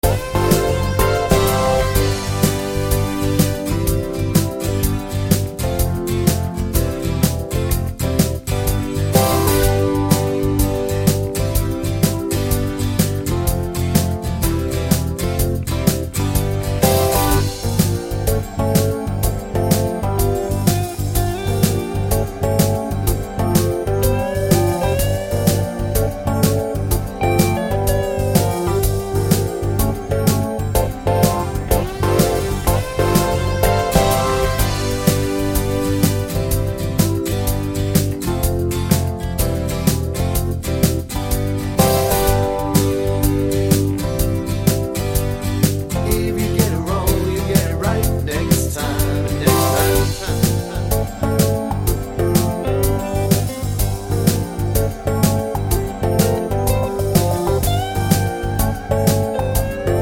no sax Pop (1970s) 3:58 Buy £1.50